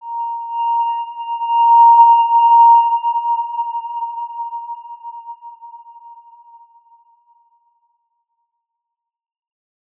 X_Windwistle-A#4-pp.wav